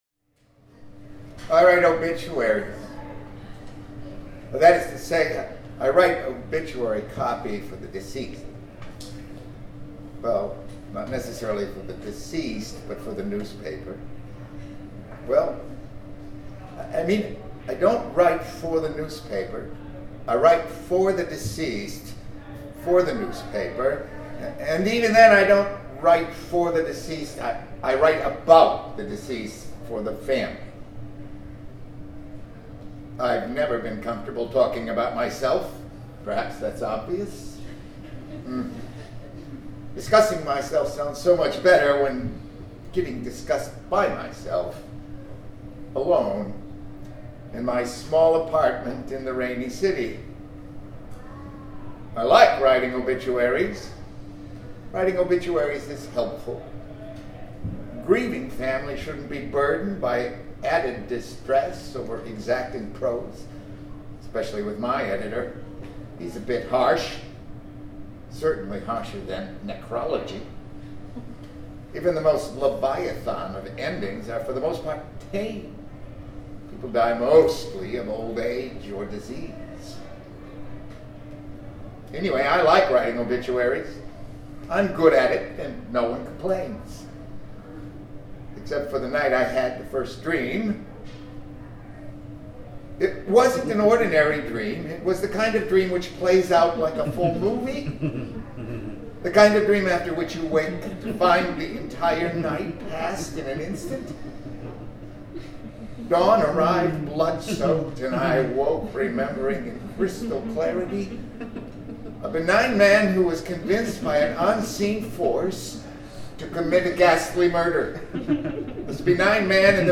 SET:   Drama
Feature Performance, The Pulp Stage
final-copy_may2014_liveaudio_thepulpstage1.m4a